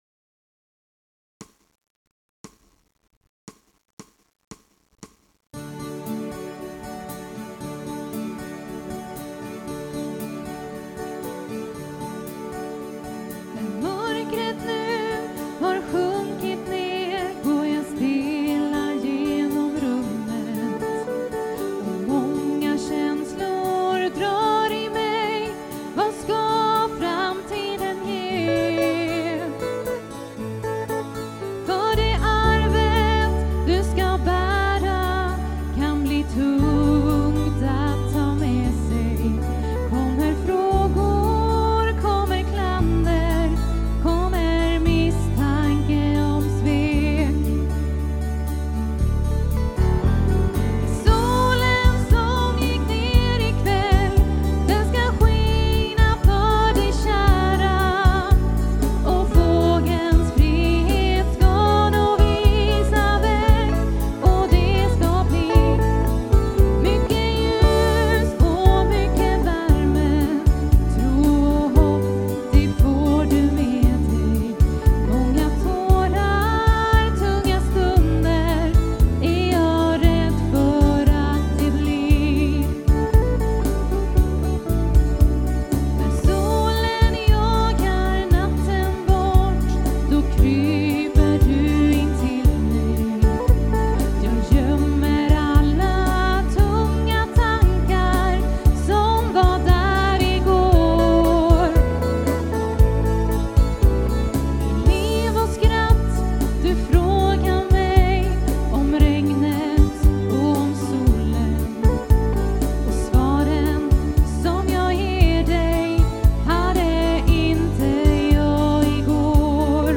• Dansband